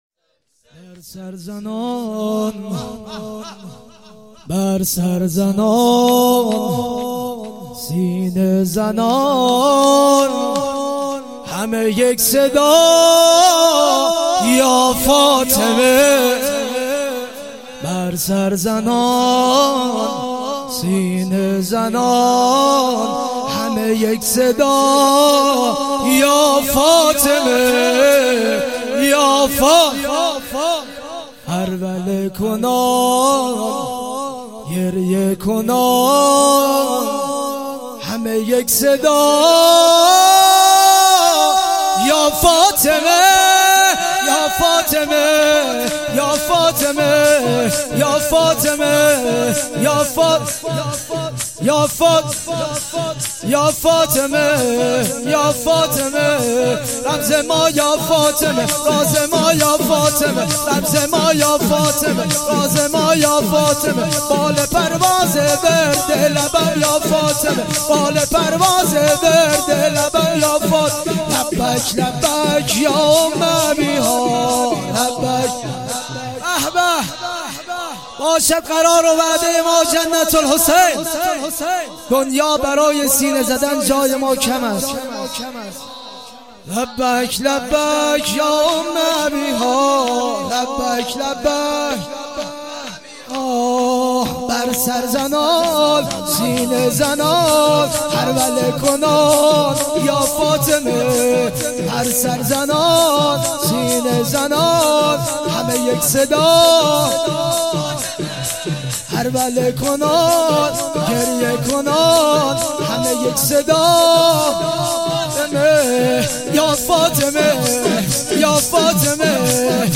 مراسم فاطمیه دوم 96.11.26